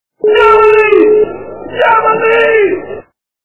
При прослушивании Иван Васильевич меняет профессию - Демоны! Демоны! качество понижено и присутствуют гудки.